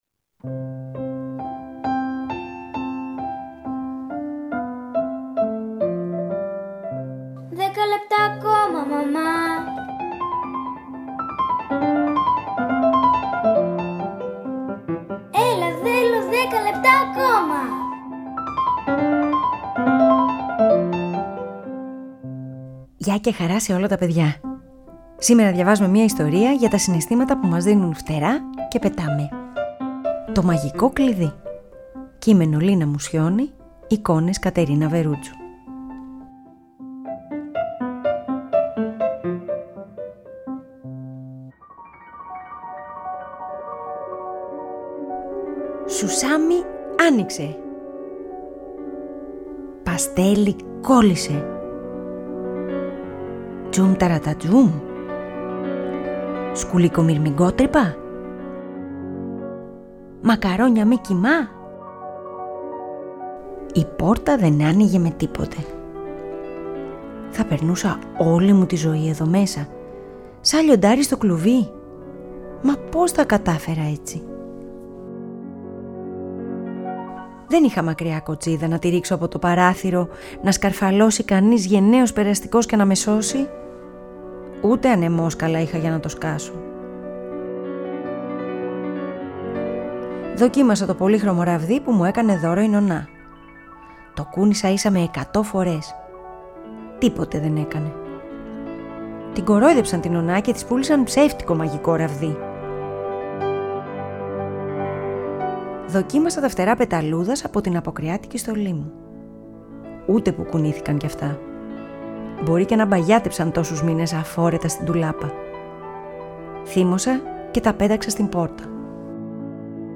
ΠΑΡΑΜΥΘΙΑ